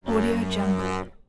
دانلود افکت صدای هواپیمای ناوبری
Sample rate 16-Bit Stereo, 44.1 kHz